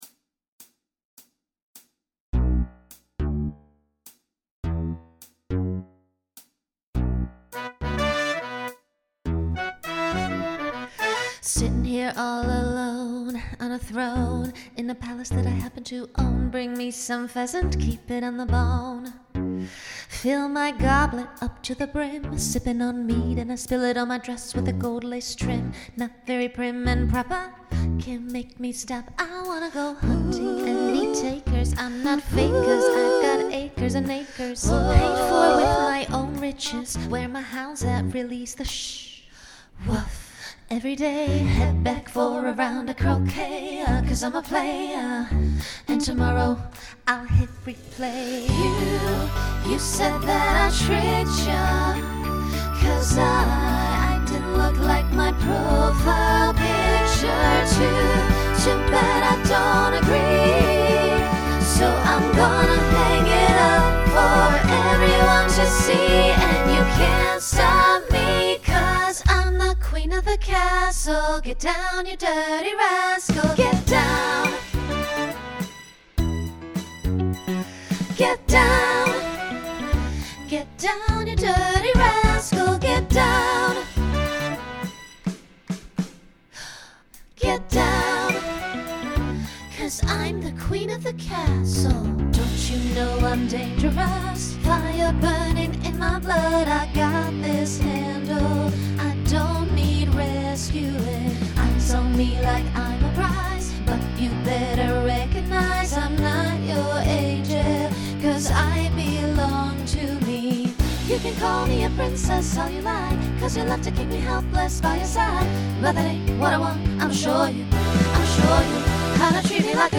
Pop/Dance Instrumental combo
Voicing SSA